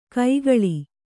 ♪ kaigaḷi